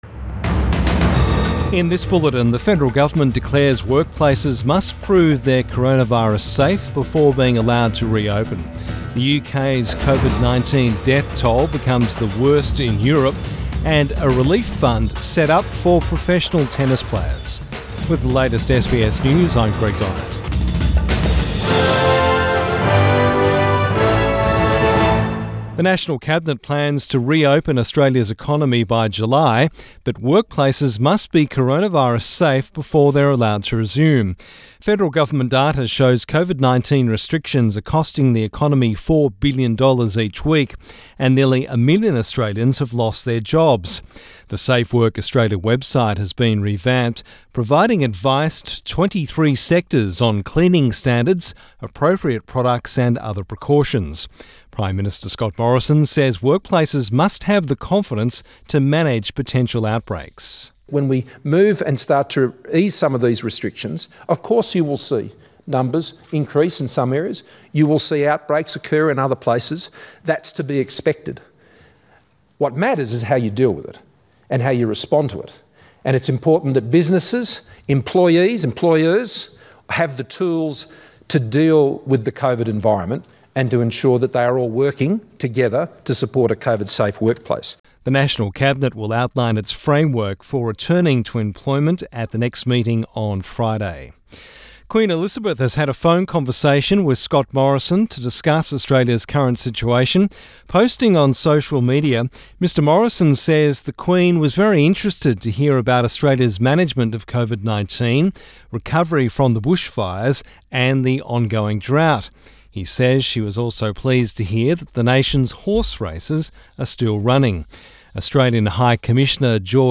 AM bulletin 6 May 2020